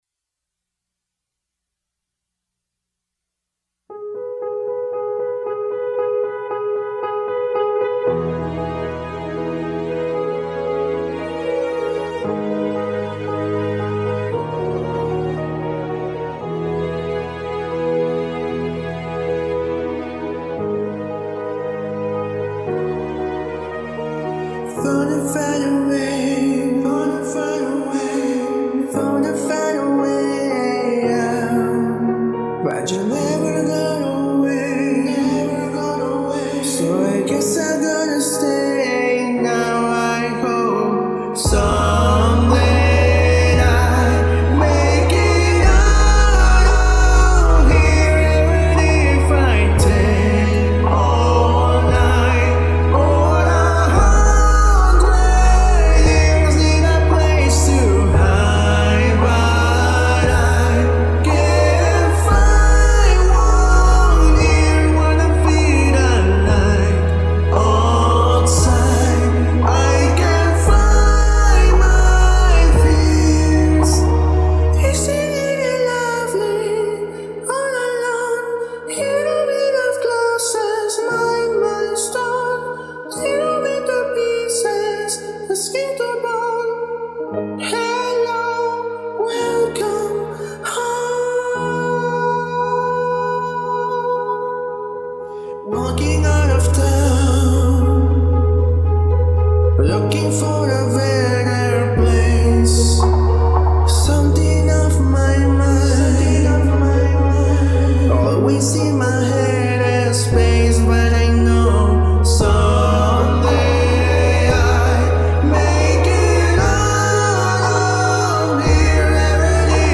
una versión acústica